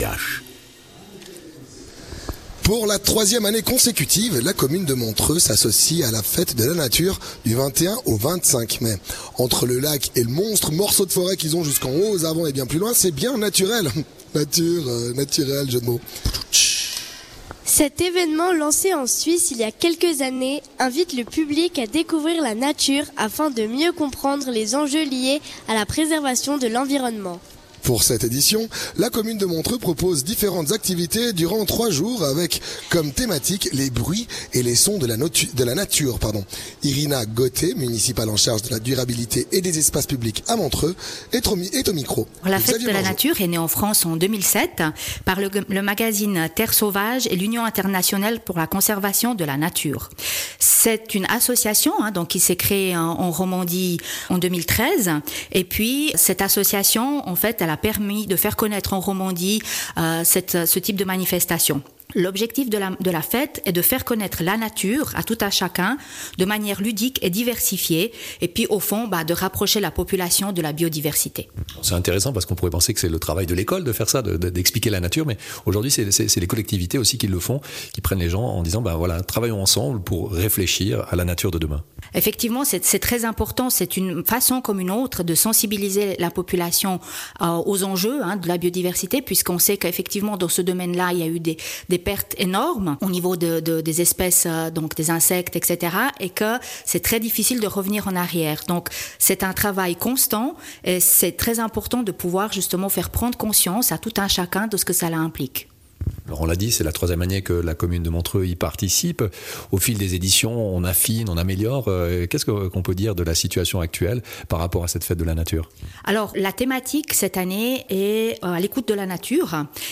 Intervenant(e) : Irina Gote, Municipale en charge de la durabilité et des espaces publics à Montreux